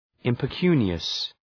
Shkrimi fonetik{,ımpı’kju:nıəs}
impecunious.mp3